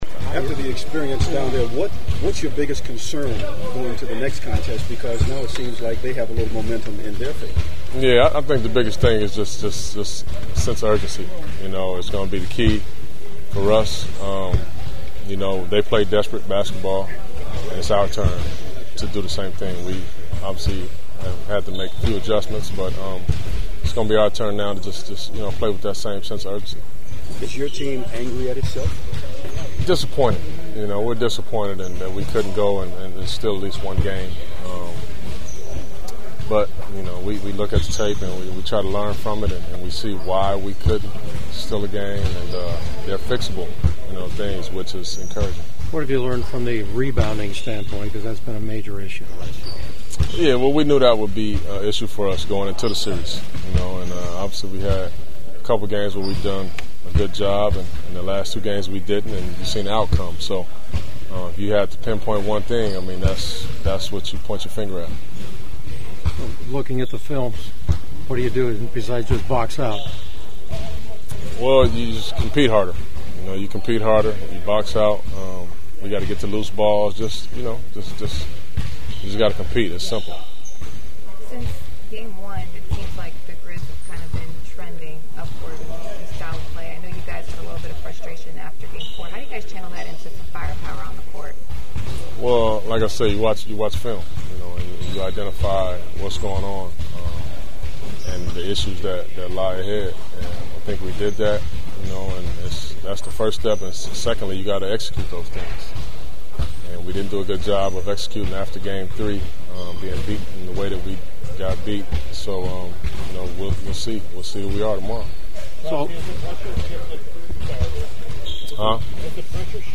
The following are our after practice chats with the Clippers…